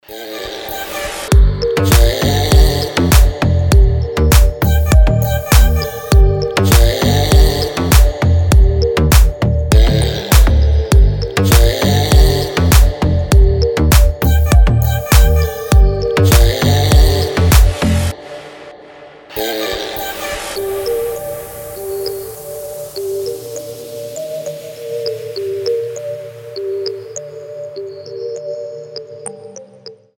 • Качество: 320, Stereo
красивая мелодия
Chill